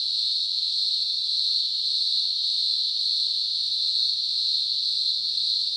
cicadas_night_loop_01.wav